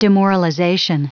Prononciation du mot demoralization en anglais (fichier audio)
Prononciation du mot : demoralization